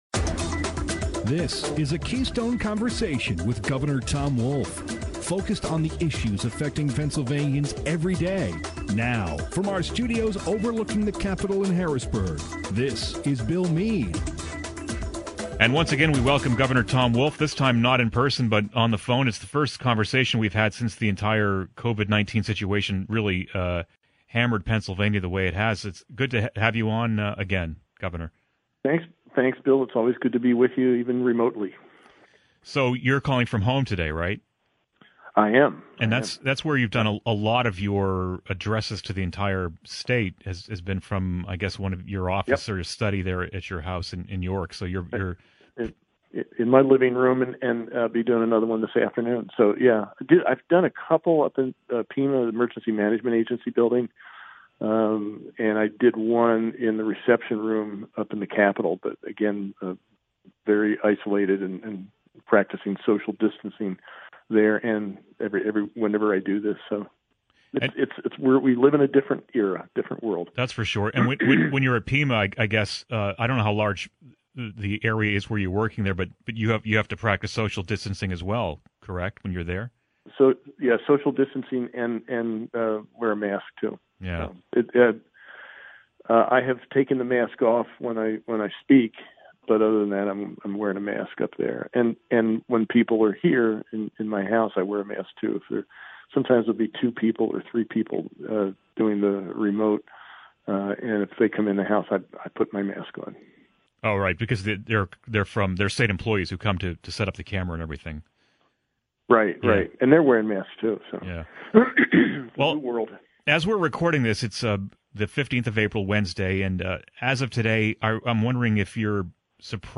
The latest edition of Keystone Conversation, Governor Tom Wolf talks about COVID-19 response.